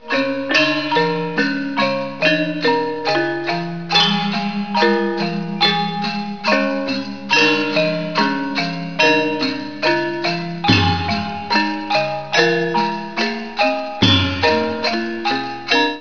Bali, métal   (345 Kb)
Un gamelan est un ensemble instrumental traditionnel indonésien composé principalement de percussions : gongs, métallophones, xylophones, tambours, cymbales, flûtes.